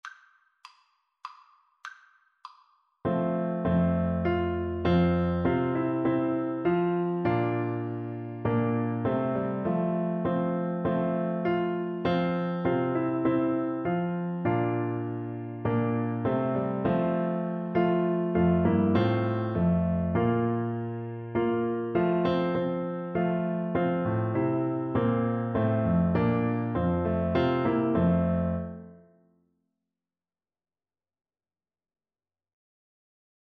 3/4 (View more 3/4 Music)
C4-D5
Classical (View more Classical Trombone Music)